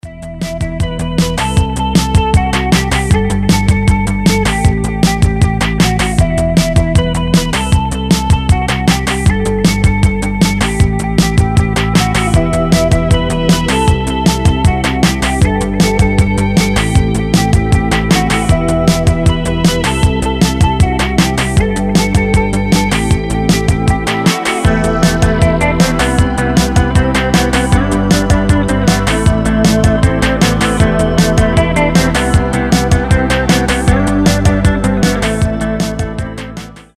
Красота пост-панка и новой волны